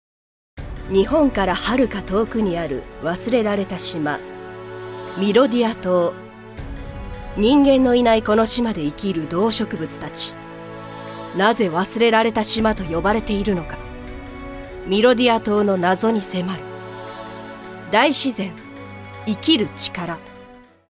◆ラジオ番組CM(明るい)◆
◆番組宣伝(低め)◆